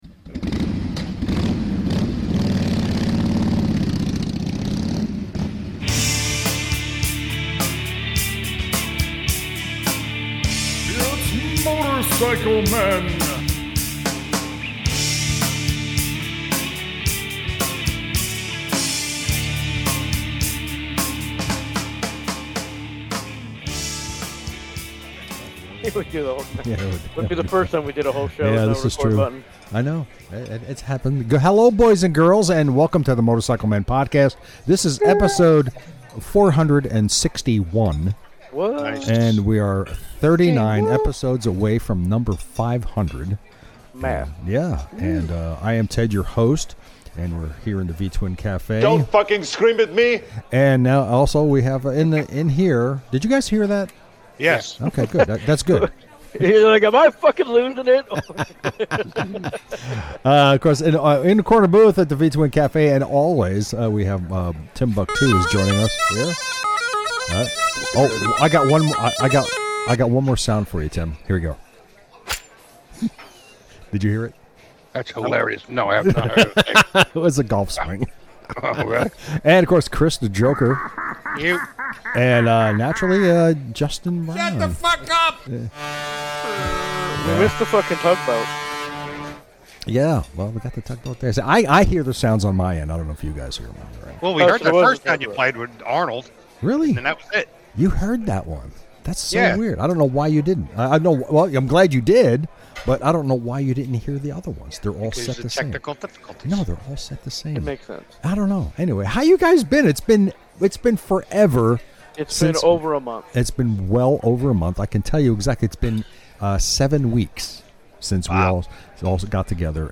The boys are all in the V-Twin Cafe' ready for some silliness and perhaps even some enlightening talk about the 2026 Harley Davidson Lineup and the future of the company. We also talk about some other things and hopefully you find it fun to listen to.